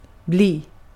Swedish Central Standard[37][38] bli [bliː] 'to become' Often realized as a sequence [ij] or [iʝ] (hear the word:
[blij]); it may also be fricated [iᶻː] or, in some regions, fricated and centralized ([ɨᶻː]).[38][39] See Swedish phonology